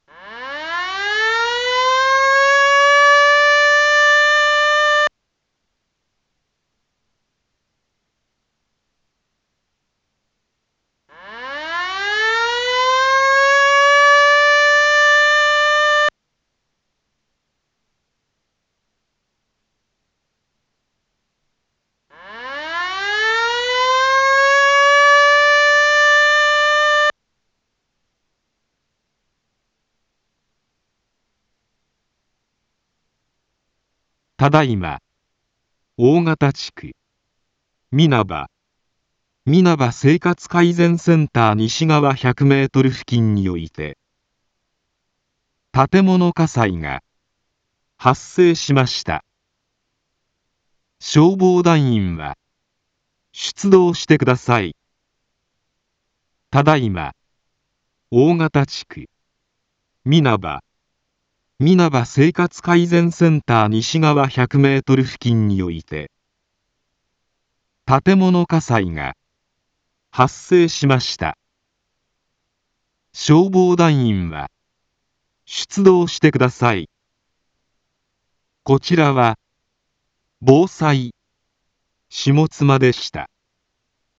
一般放送情報
Back Home 一般放送情報 音声放送 再生 一般放送情報 登録日時：2021-12-13 20:33:28 タイトル：火災報 インフォメーション：ただいま、大形地区、皆葉、皆葉生活改善センター西側100メートル付近において 建物火災が発生しました。